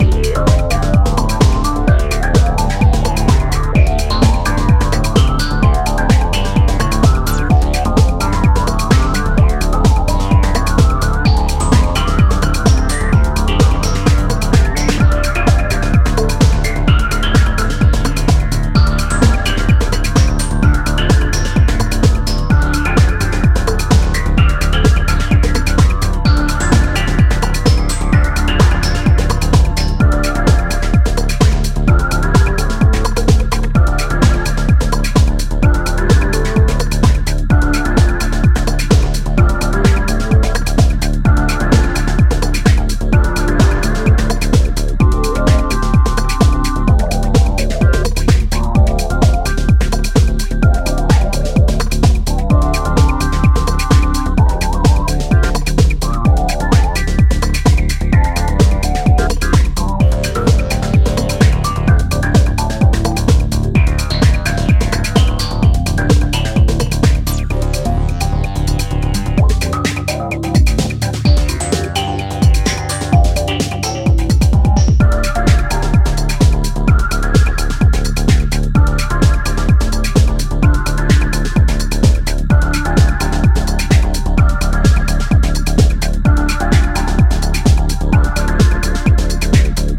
初期デトロイト的ムードも醸すオプティミスティックかつメランコリックなメロディーが心地良い